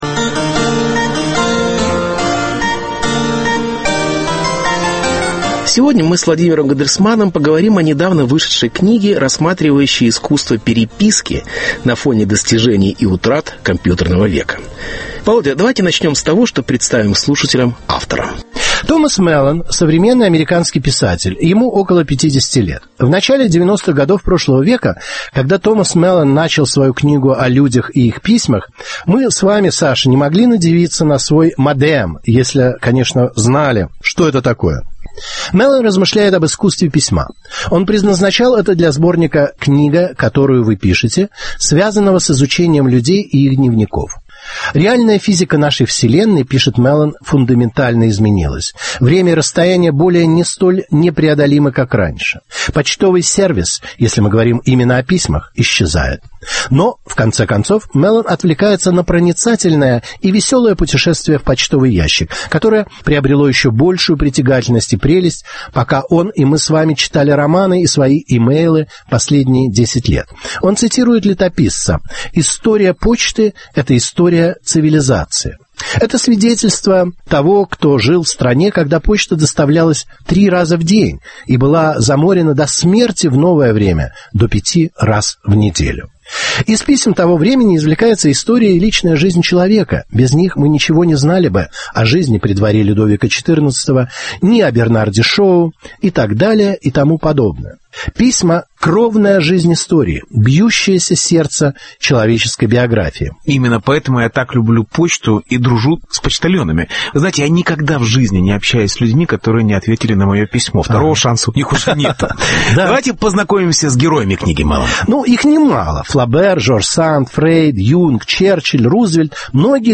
Эпистолярное искусство и компьютерный век. Беседа с Владимиром Гандельсманом.